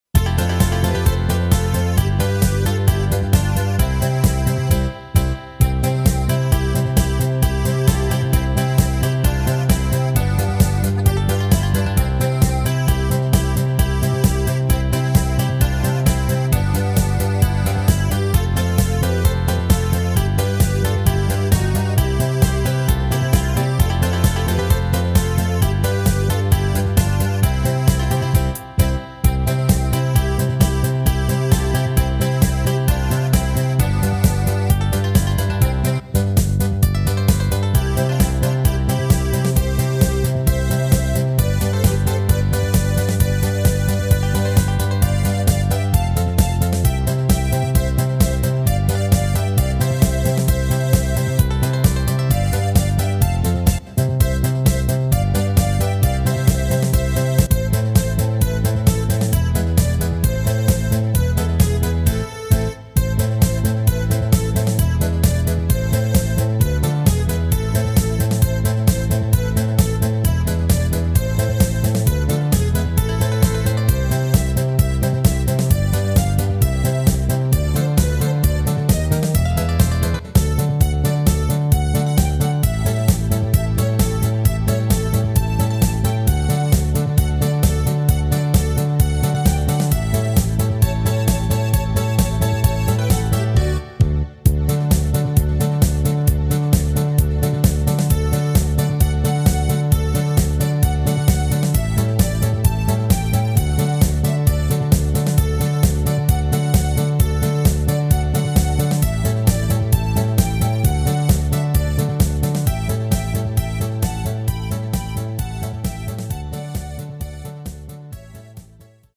Rubrika: Národní, lidové, dechovka
- směs - polka
Karaoke